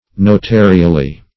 notarially - definition of notarially - synonyms, pronunciation, spelling from Free Dictionary Search Result for " notarially" : The Collaborative International Dictionary of English v.0.48: Notarially \No*ta"ri*al*ly\, adv. In a notarial manner.
notarially.mp3